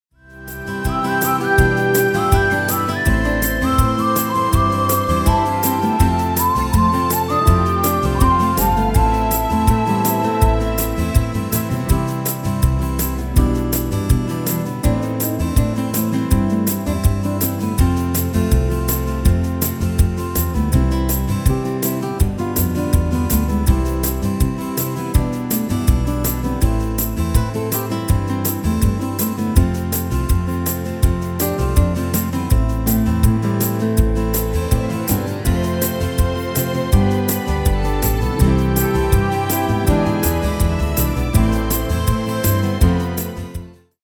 Extended MIDI File Euro 12.00